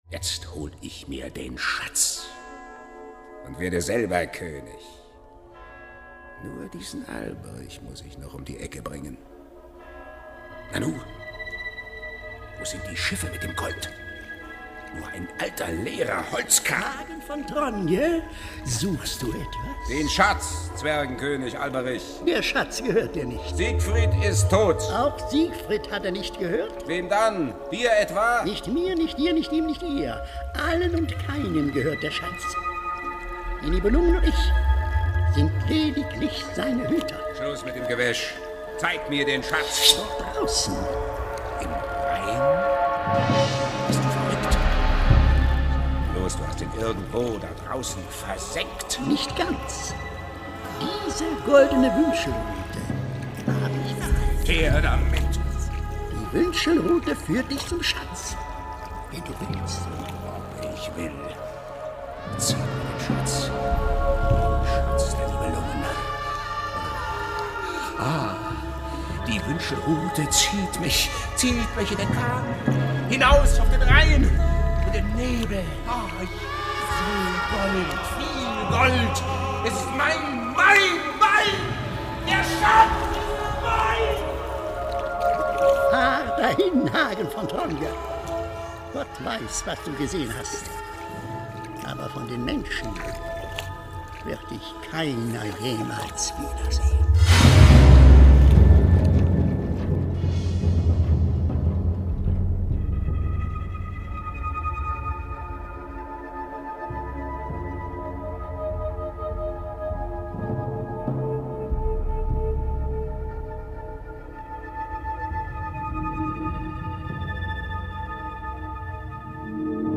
Oboe, Oboe d'amore
Vioela (Rennaissancelaute)
Stimmen, Gesang der Rheintöchter
Tasteninstrumente, Schlagwerk, Maultrommel